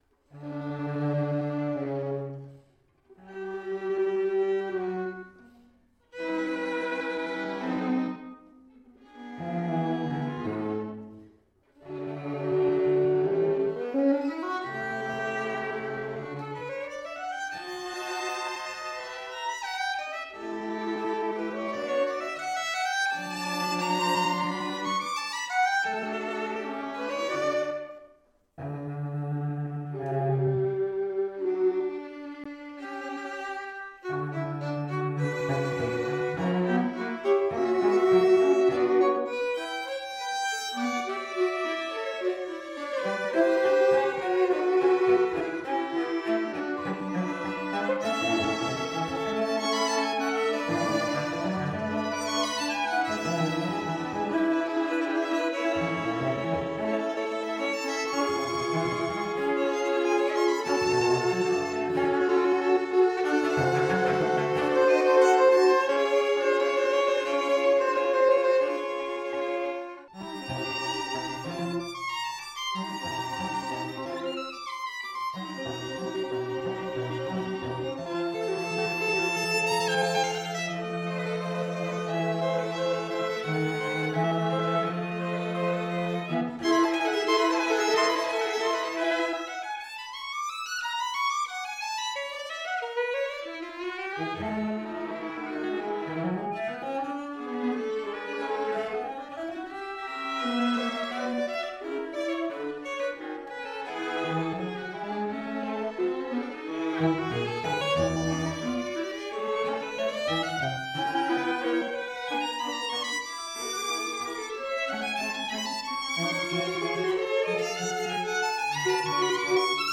The pace is leisurely but after a while the tempo hastens.